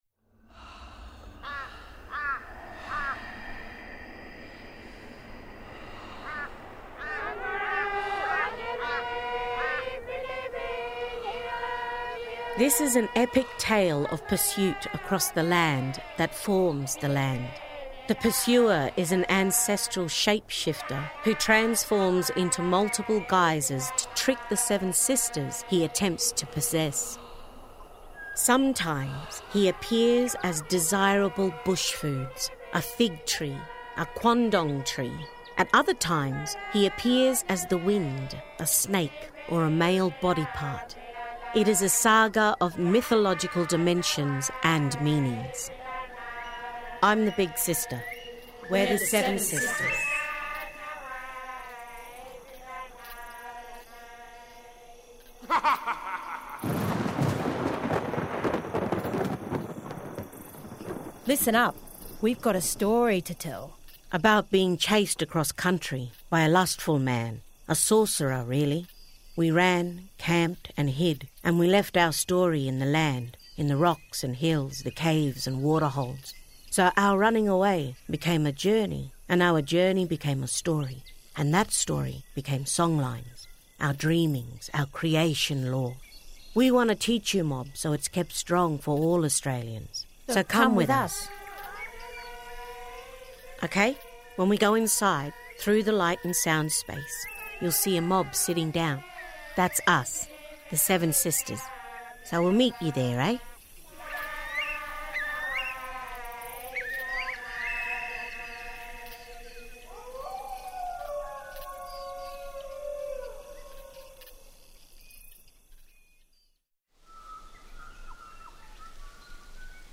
Songlines: Tracking the Seven Sisters 15 Sep 2017 Songlines audio journey Connect with the Seven Sisters as they travel across the land pursued by a mischievous shape-shifter in this audio tour originally developed for the Songlines exhibition.